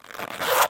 Звуки ширинки
Звук расстёгивания ширинки для монтажа